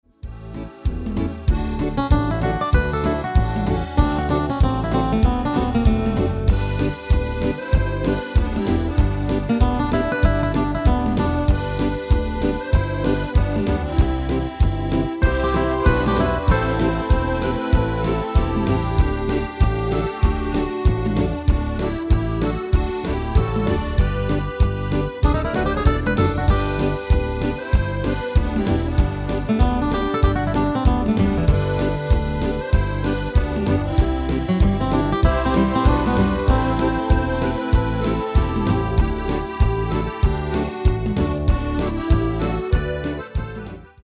Demo/Koop midifile
Genre: Nederlands amusement / volks
Toonsoort: D/Eb
- Vocal harmony tracks